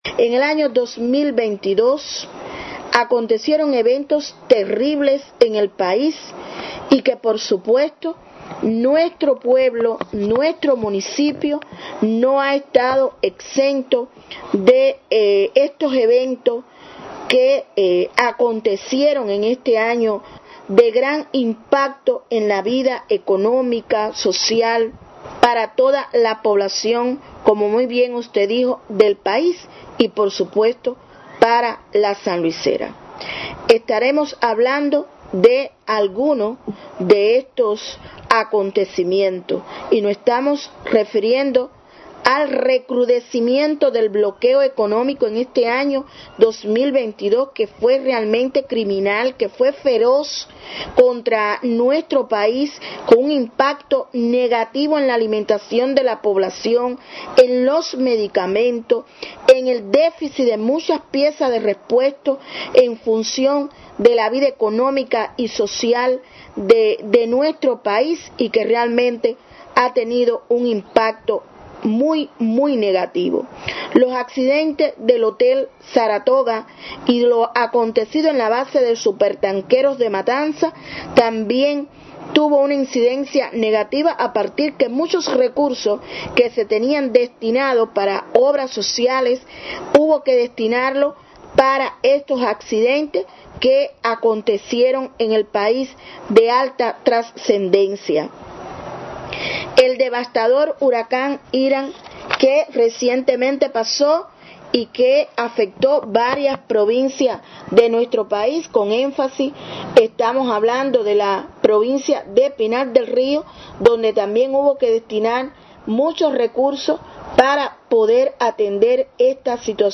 Escuche detalles aquí a través de la entrevista realizada a la Presidenta de la Asamblea Municipal del Poder Popular, Yazmín Tortoza Sánchez.